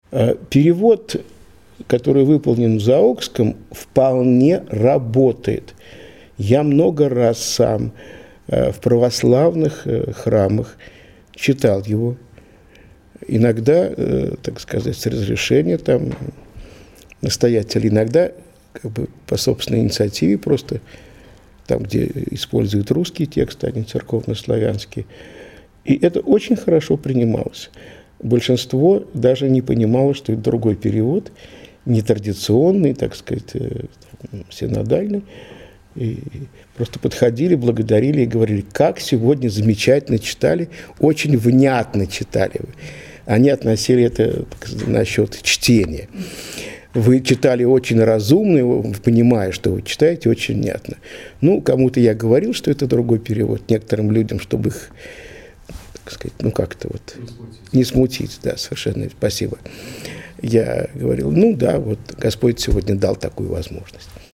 КОНФЕРЕНЦИЯ 2011
Фрагменты выступлений в формате mp3.